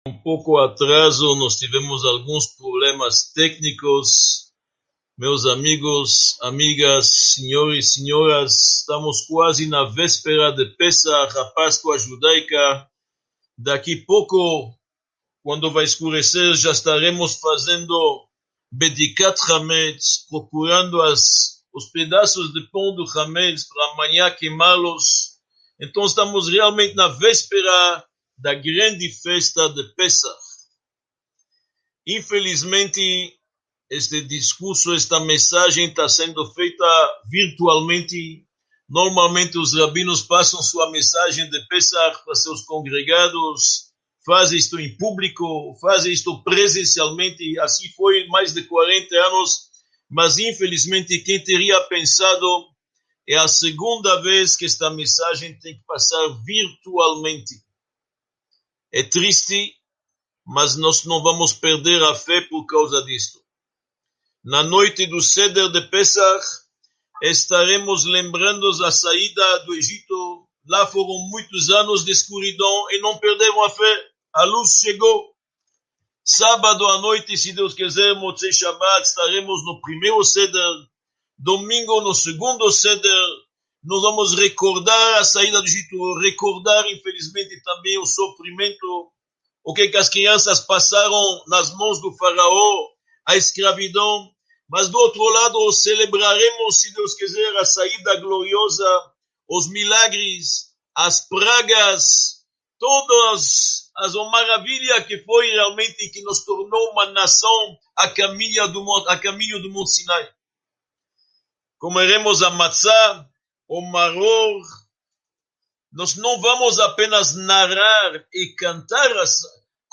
26 – Uma mensagem sobre Pêssach | Módulo I – Aula 26 | Manual Judaico